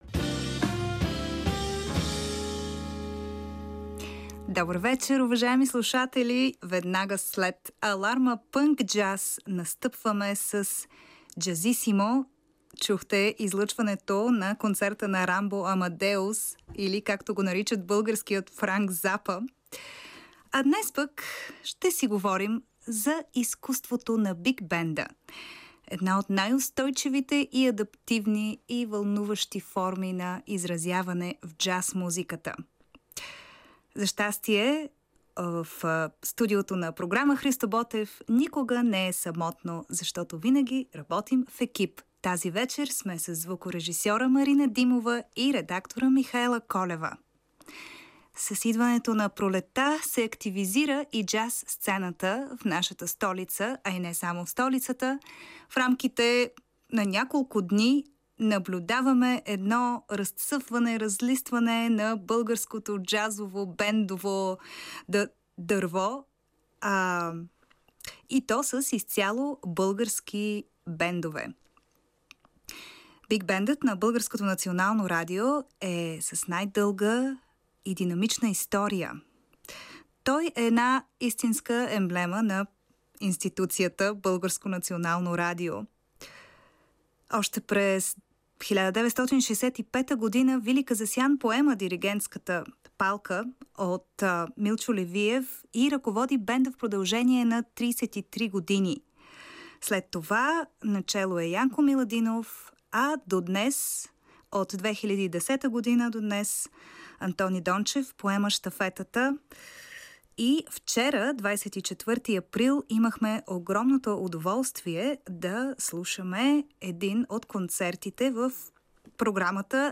Слушайте Jazzissimo в петък от 23:00 по програма „Христо Ботев“ на БНР.